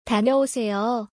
タニョオセヨ